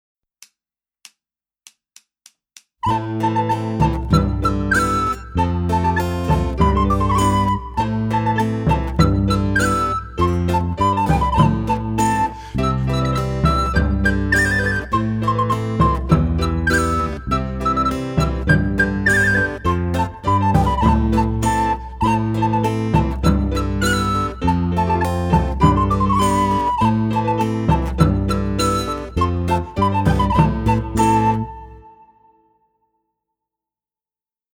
Gattung: Sopranblockflöte/Klavier/CD